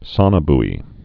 (sŏnə-bē, -boi)